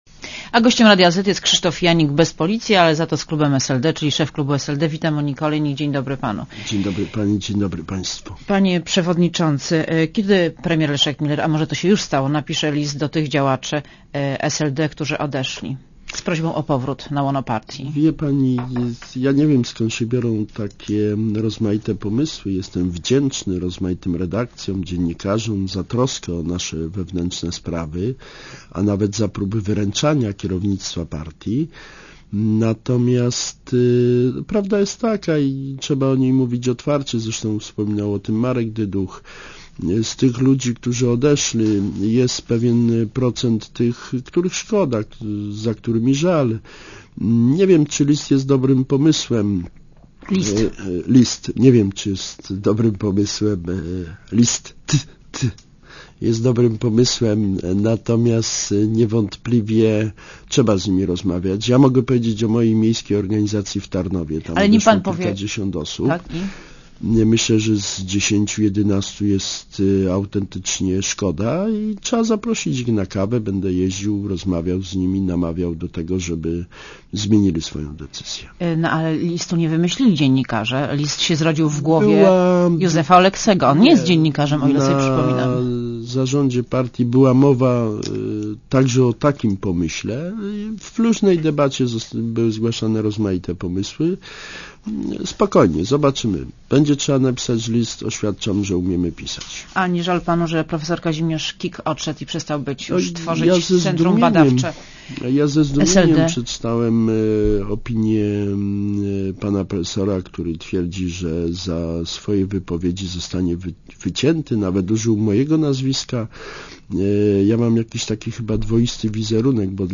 Krzysztof Janik w Radiu Zet (RadioZet)